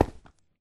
Sound / Minecraft / step / stone6.ogg
stone6.ogg